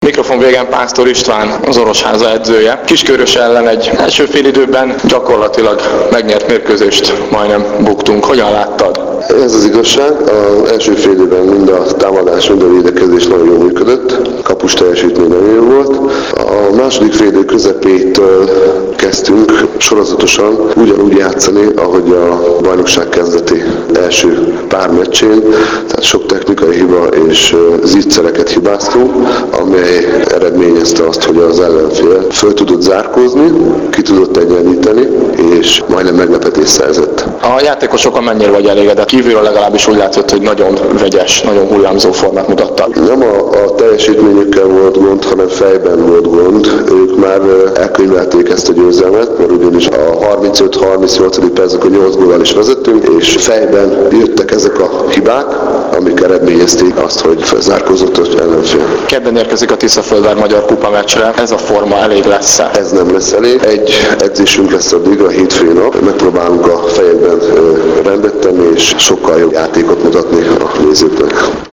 Az interjúk mp3 formátumban hallgathatók meg.